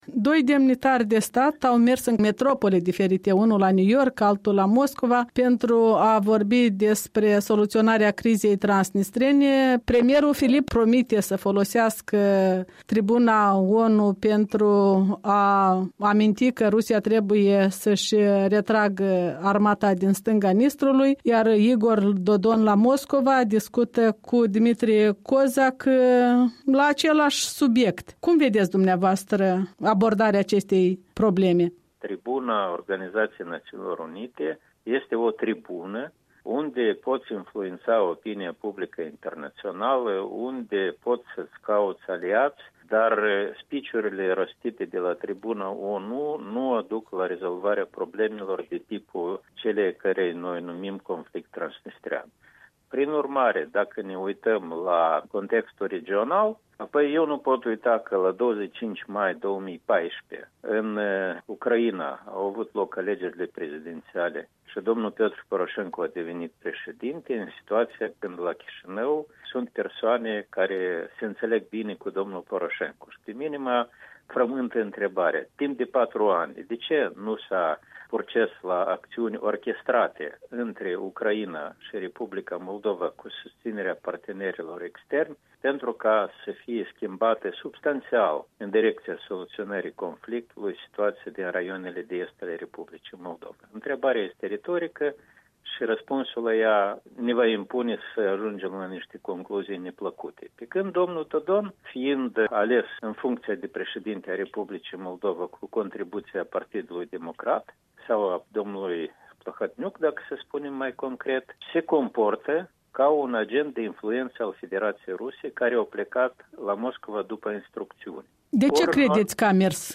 Interviu cu analistul politic Oazu Nantoi